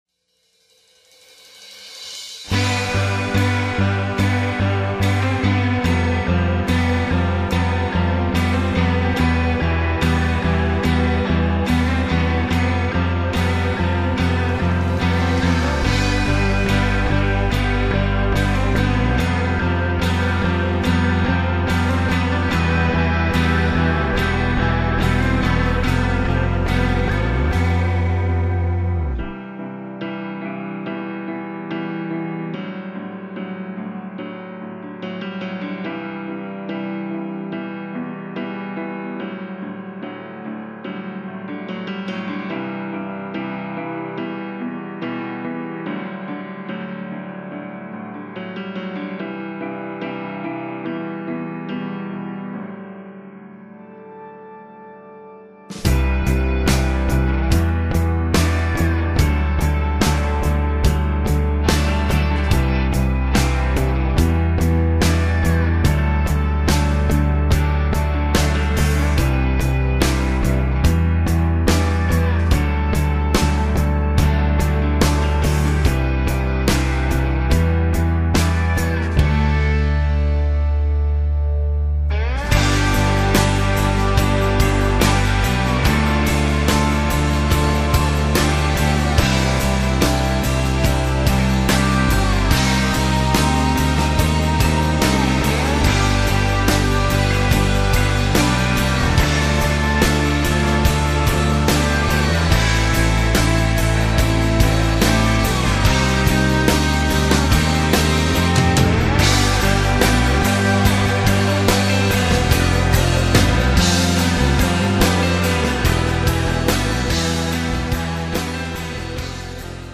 B/Trax – Medium Key without Backing Vocals